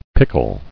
[pick·le]